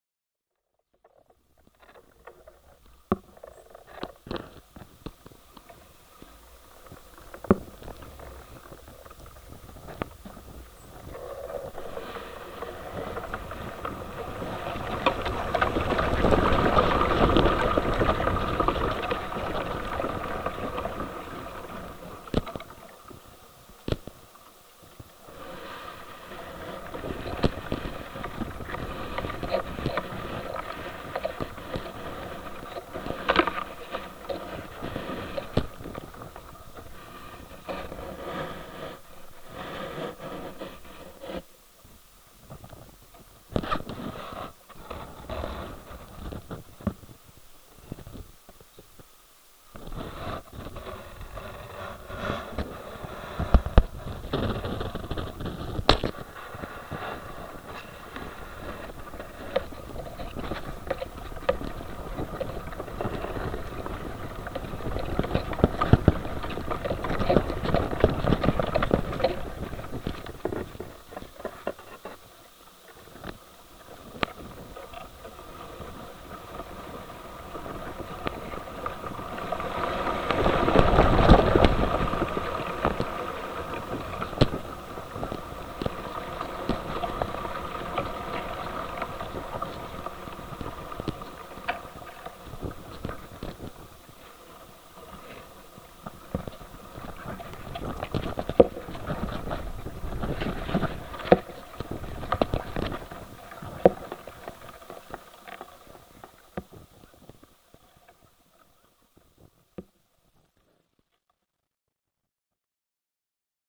sound art
Recording from a low hanging branch, the performer's knocks and scratches mix with the jostling twigs at branch's end.
Nature sounds
Improvisation in art